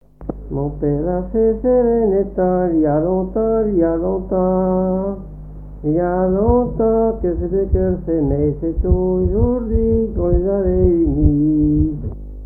circonstance : fiançaille, noce
Genre laisse
Pièce musicale inédite